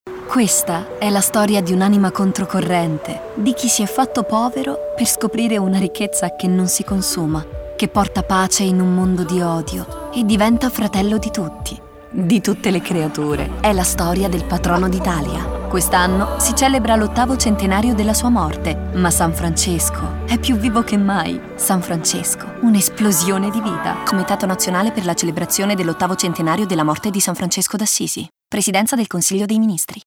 Lo spot radio
san-francesco-radio.mp3